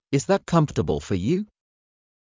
ｲｽﾞ ｻﾞｯﾄ ｶﾝﾌｧﾀﾎﾞｳ ﾌｫｰ ﾕｳ